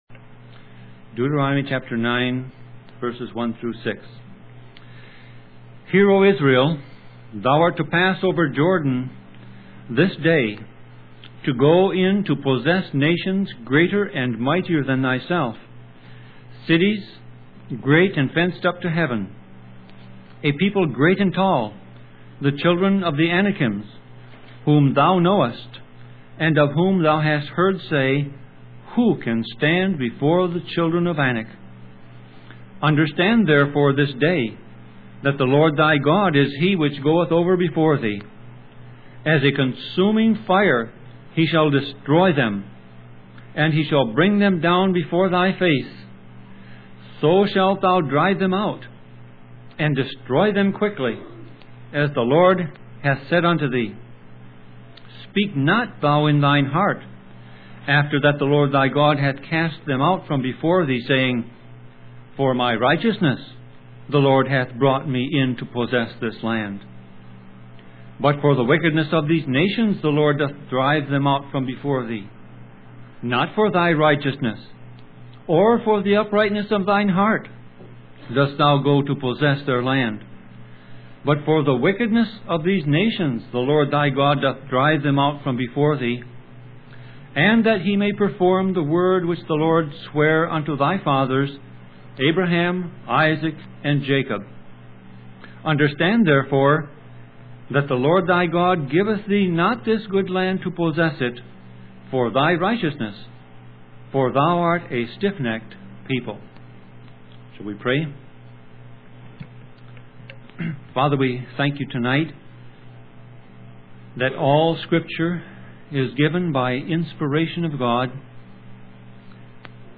Sermon Audio Passage: Deuteronomy 9:1-6 Service Type